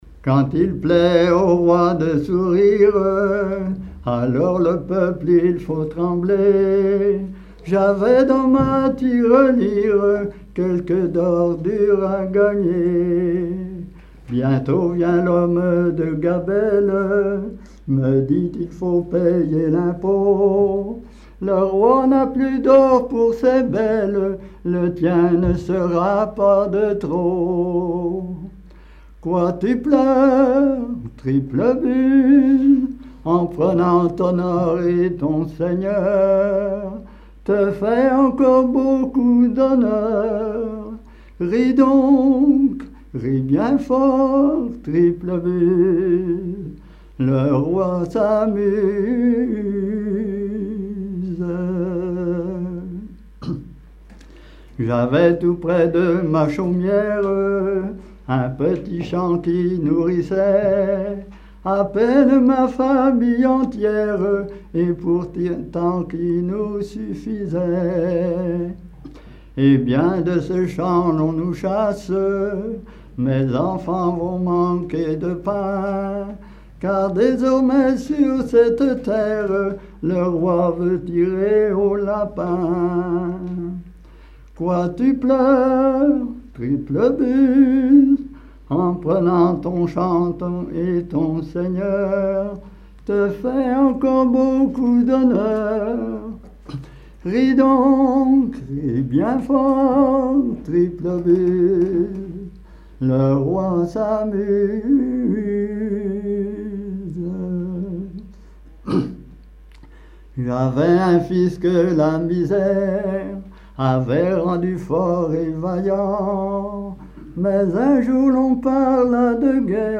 Genre strophique
Veillée
Pièce musicale inédite